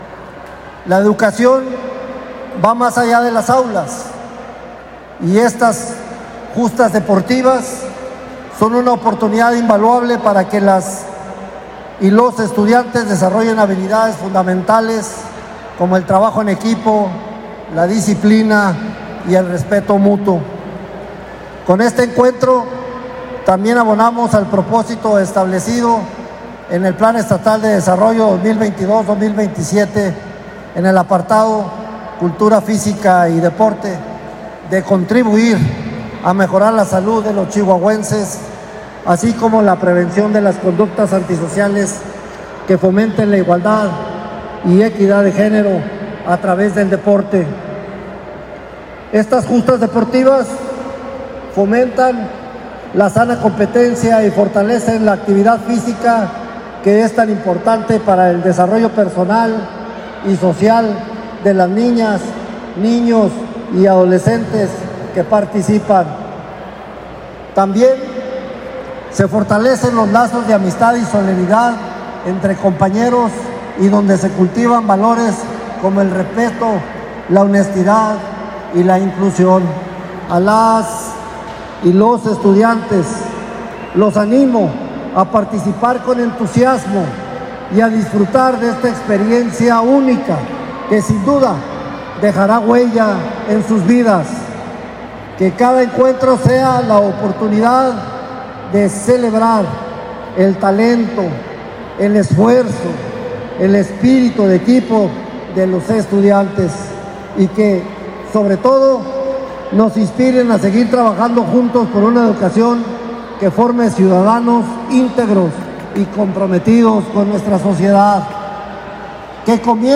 La inauguración de los Juegos Deportivos 2023-2024 estuvo a cargo del secretario de Educación y Deporte, Francisco Hugo Gutiérrez Dávila, quien destacó la relevancia de efectuar este tipo de actividades de sana recreación que ayudan y fomentan la formación integral del alumnado, con la promoción de los valores y el compañerismo a través del deporte.
AUDIO-SECRETARIO-DE-EDUCACION-CONVIVENCIAS-DEPORTIVAS.mp3